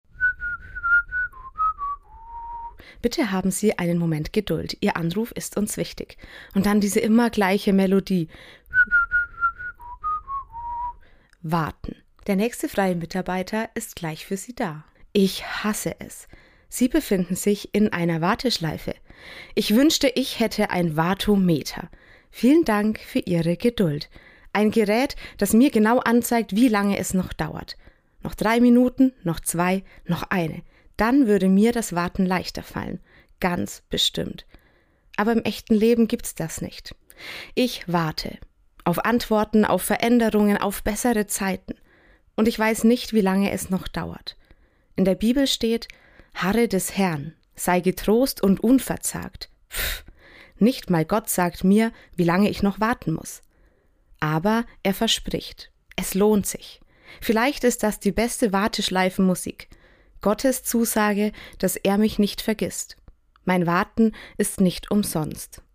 Autorin und Sprecherin